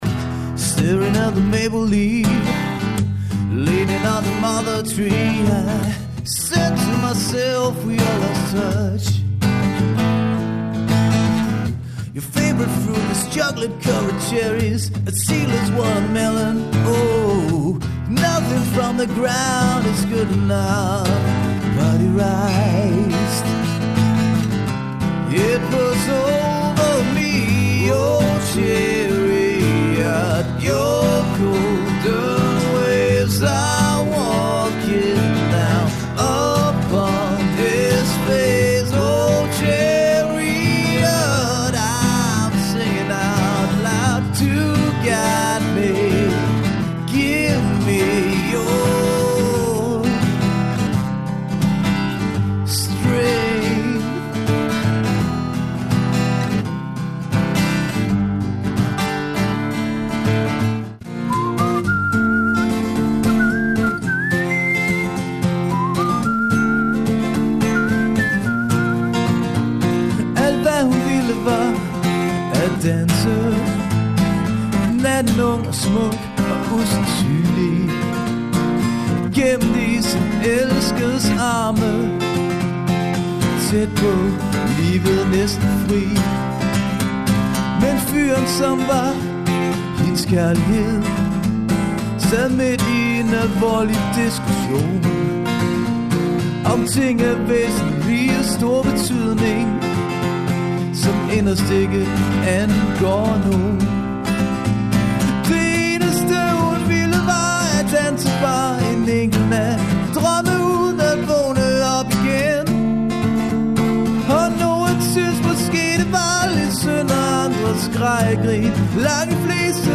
Udelukkende radiohits.
Festband fra Nørresundby